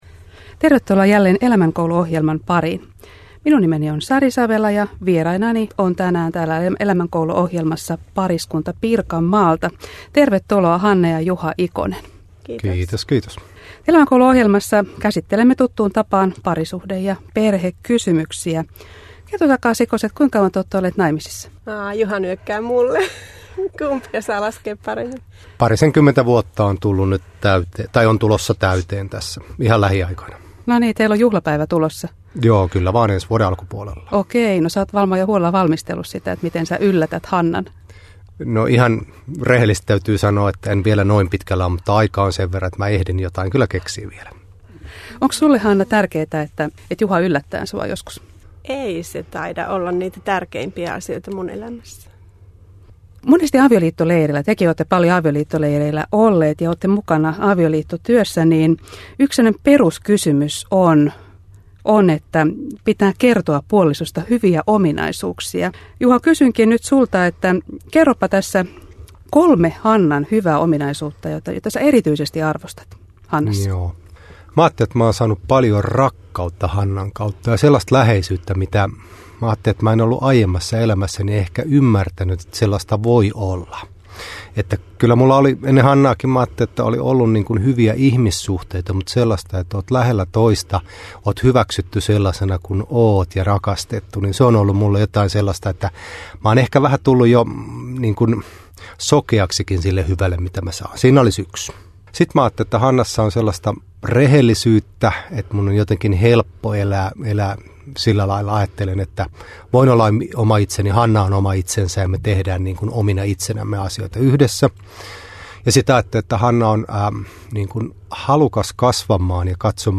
vieraina Elämänkoulu-ohjelmassa